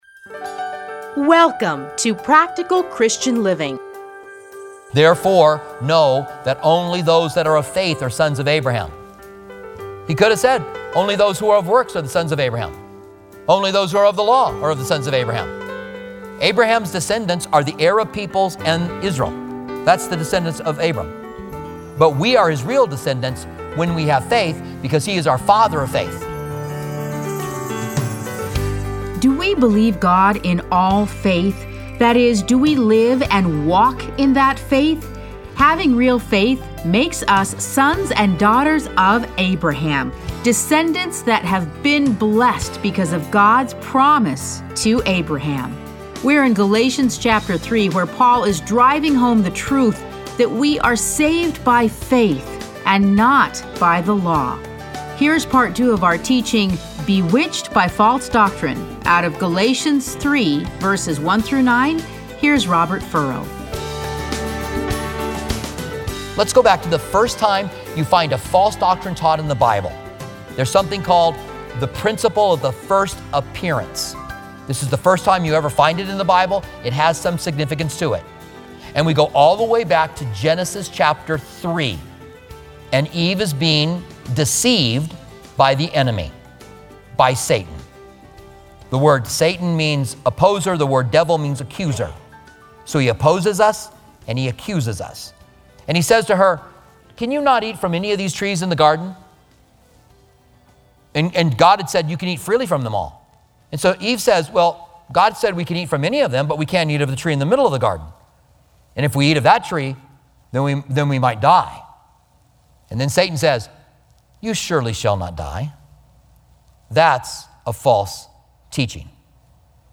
Listen to a teaching from Galatians 3:1-9.